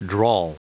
Prononciation du mot drawl en anglais (fichier audio)
Prononciation du mot : drawl